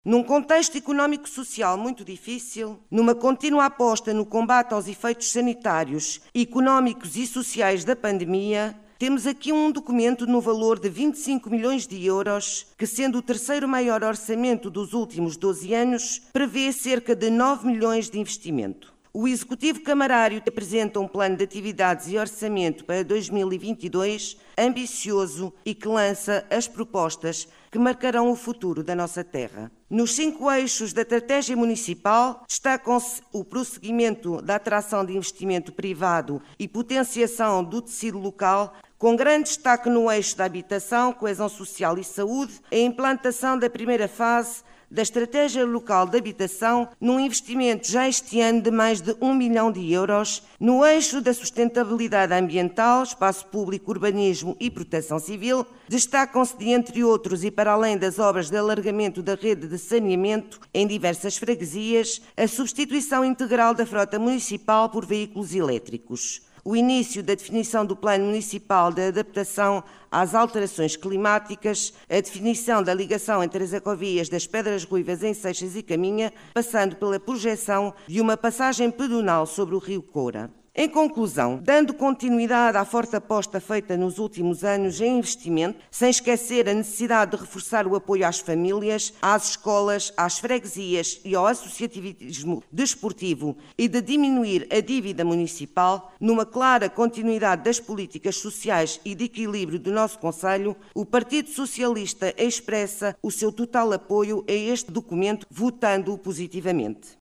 Realizada no cineteatro dos bombeiros voluntários de Vila Praia de Âncora, decorreu em tom de crispação de início ao fim.